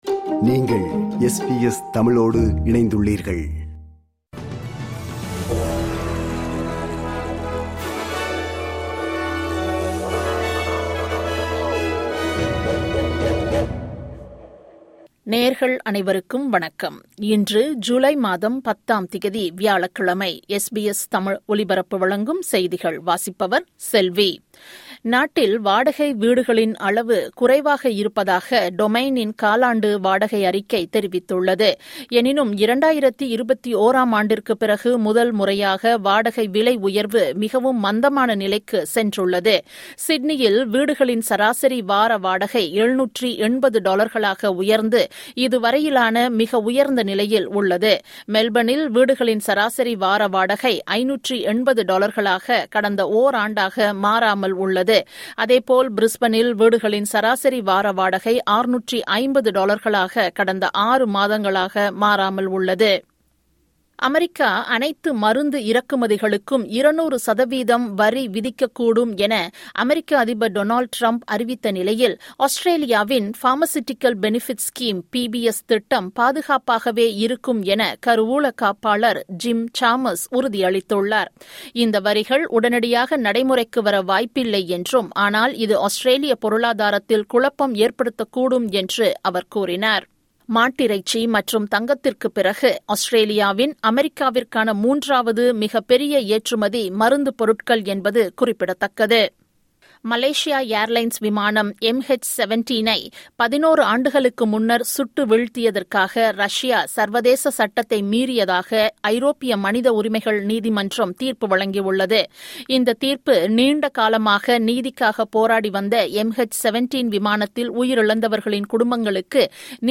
SBS தமிழ் ஒலிபரப்பின் இன்றைய (வியாழக்கிழமை 10/07/2025) செய்திகள்.